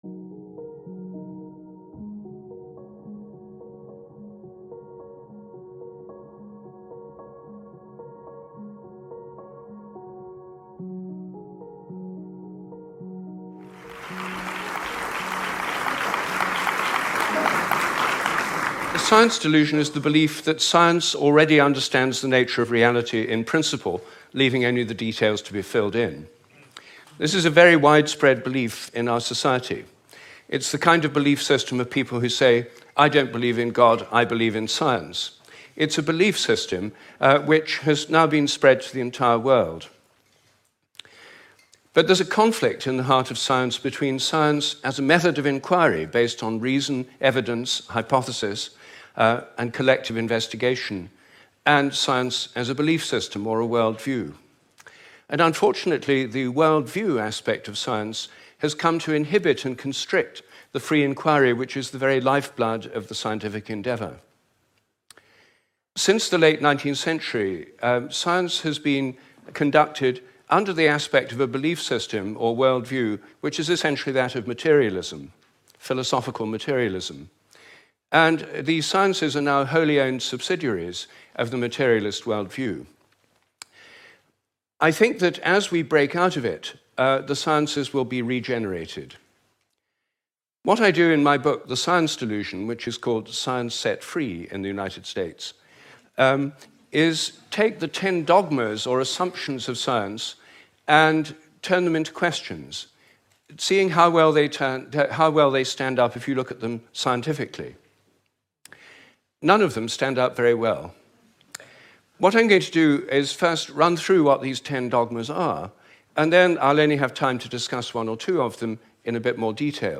Banned, deleted, and canceled Ted Talk on true science